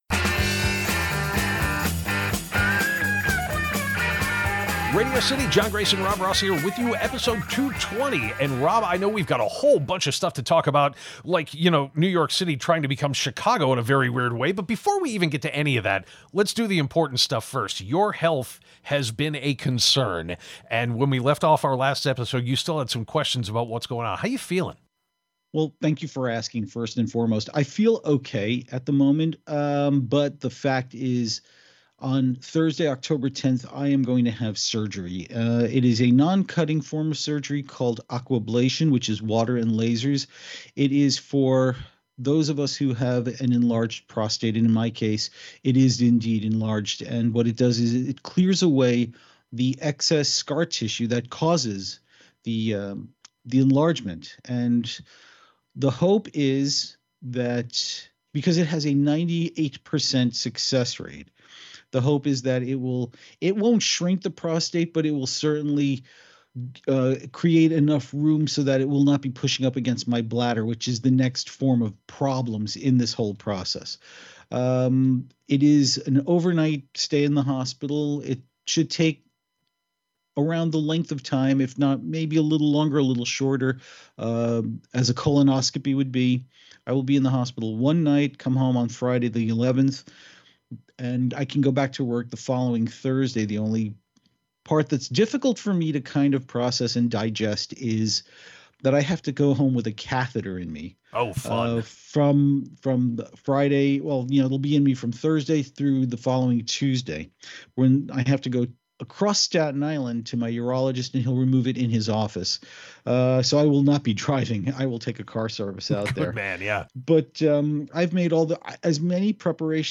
There are some very poignant moments during this conversation, which you most assuredly do not want to miss.